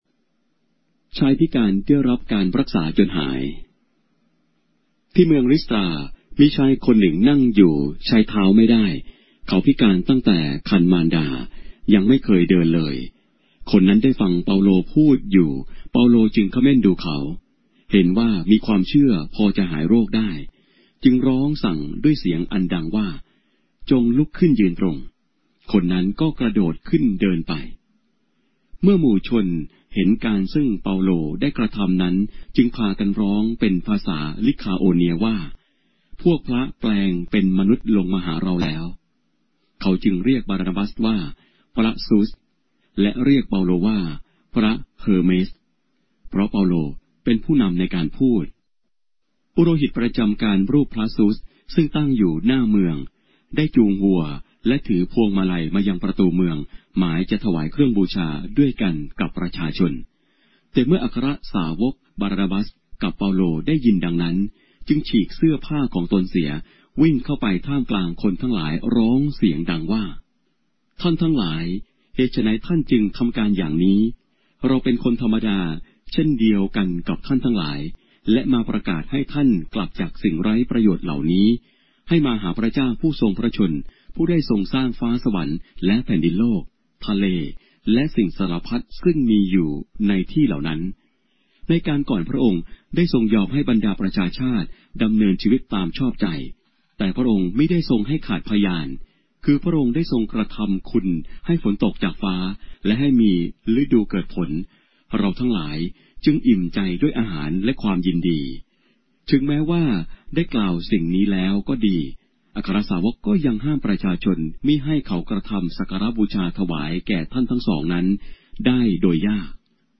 บทของพระคัมภีร์ไทย - มีคำบรรยายเสียง - Acts, chapter 14 of the Holy Bible in Thai